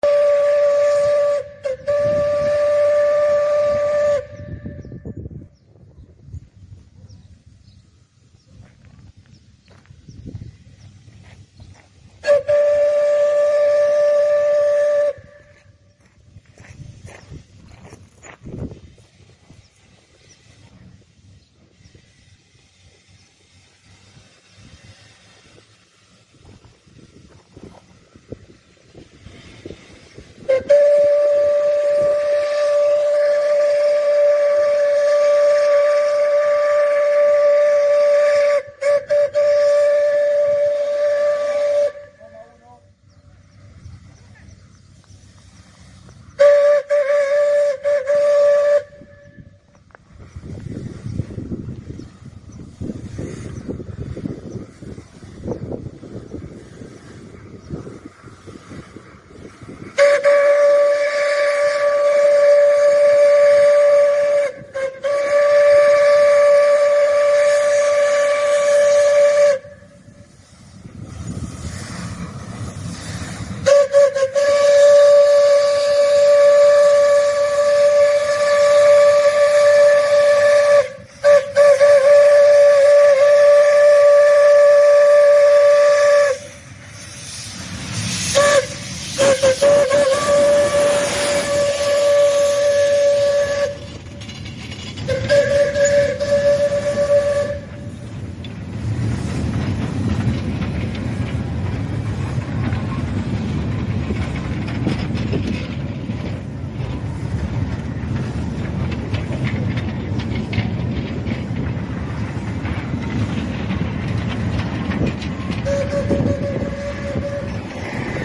Hacé click y escuchá los sonidos mas carácterísticos de La Trochita
BOCINA.mp3